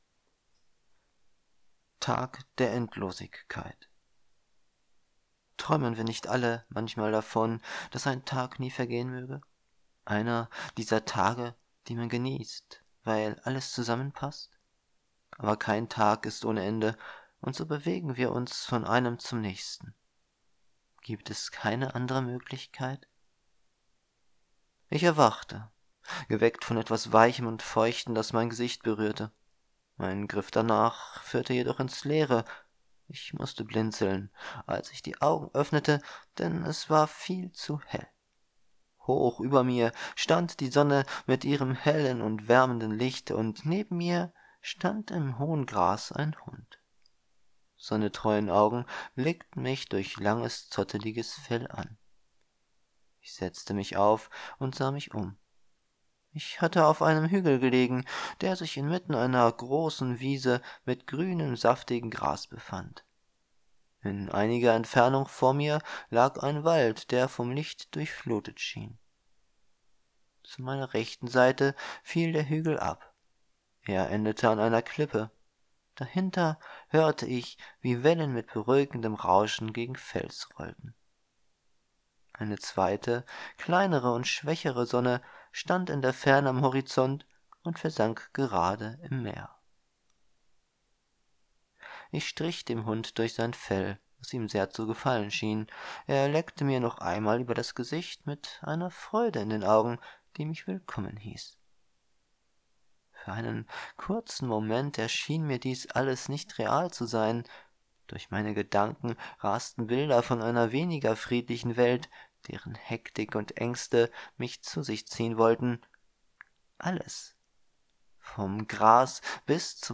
Ebook (pdf) Ebook (epub) AudioBook (mp3) Video Tag der Endlosigkeit Geschrieben von Florian Fehring Träumen wir nicht alle manchmal davon, dass ein Tag nie vergehen möge?